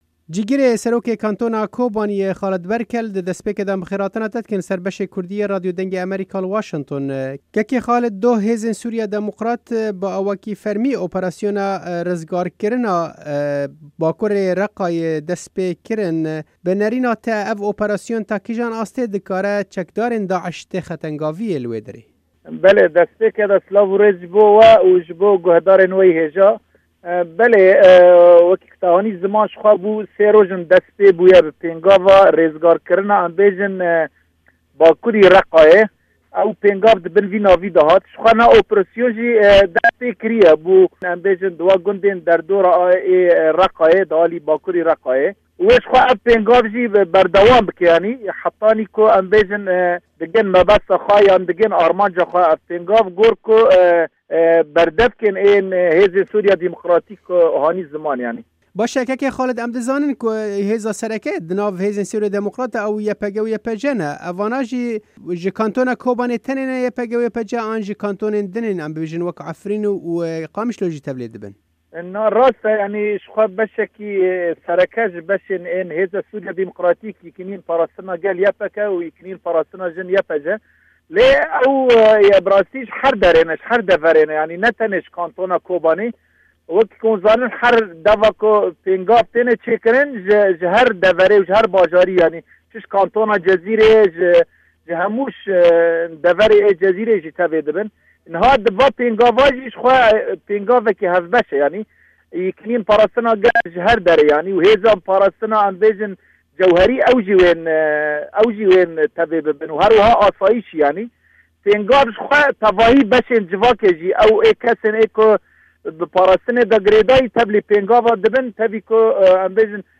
Cîgirê serokê Kantona Kobaniyê Xalid Berkel di hevpeyvînekê de li gel Deng Amerîka behsa vê operasyonê kir û got: